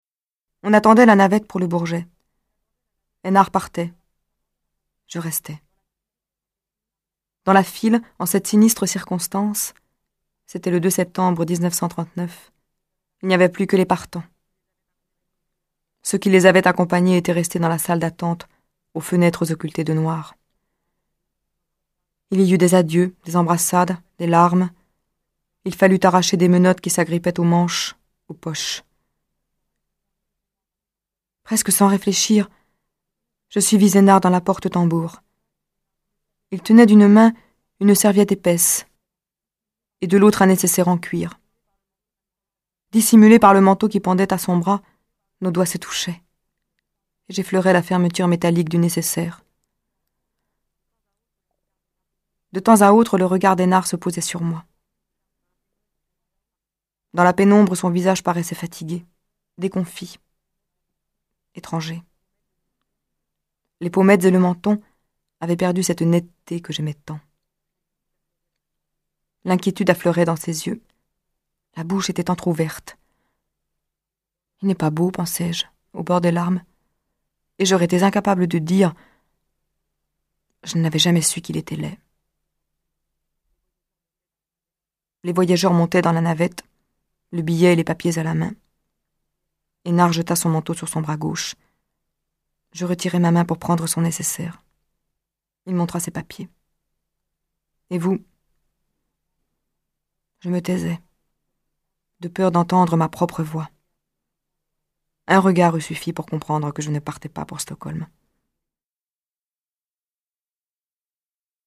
C’est l’occasion d’écouter les livres audio enregistrés dans la collection La Bibliothèque des voix des éditions des femmes-Antoinette Fouque.
Écoutez Isabelle Huppert vous faire la lecture des extraits :